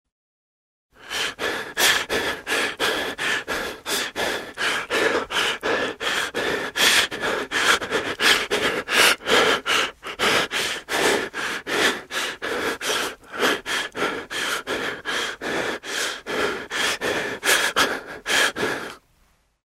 На этой странице собраны натуральные звуки человеческого тела: сердцебиение, дыхание и другие физиологические процессы.
Тяжелое мужское дыхание